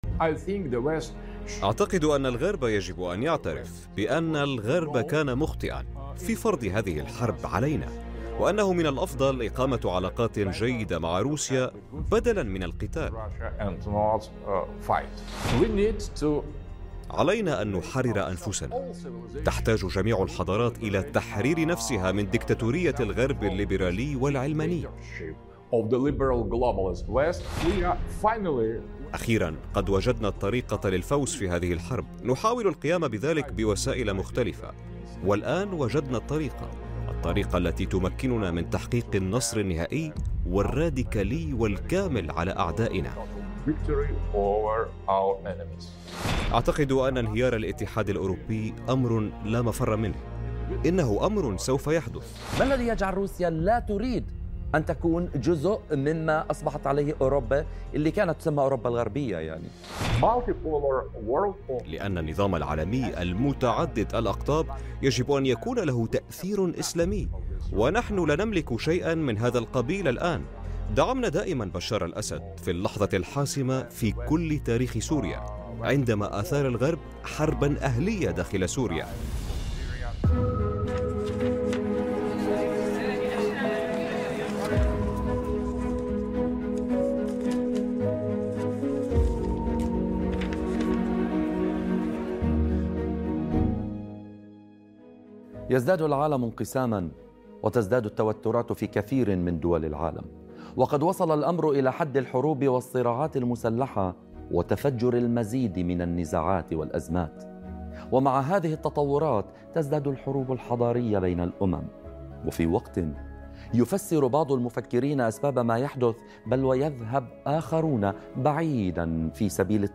حوار سياسي